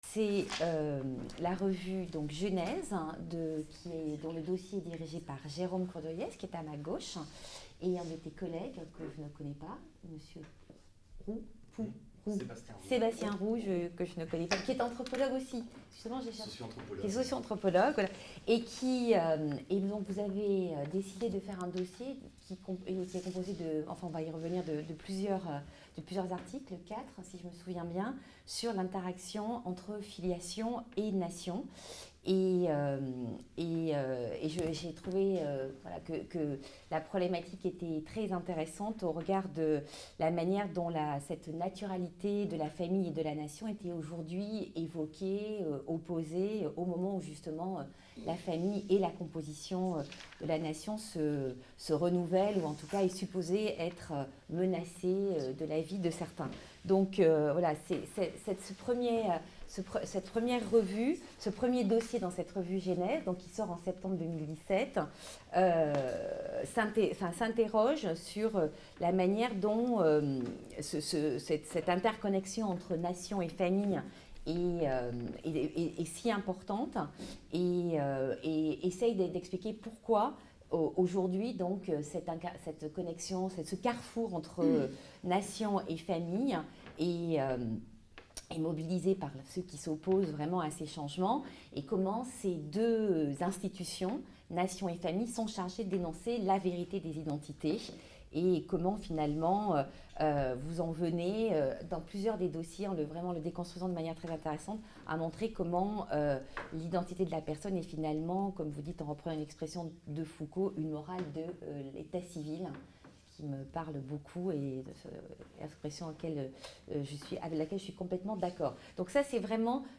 Séminaire "Corps" et sciences sociales- Présentation critique d'ouvrages 1 ère Partie | Canal U